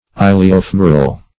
Search Result for " iliofemoral" : The Collaborative International Dictionary of English v.0.48: Iliofemoral \Il`i*o*fem"o*ral\, a. (Anat.) Pertaining to the ilium and femur; as, iliofemoral ligaments.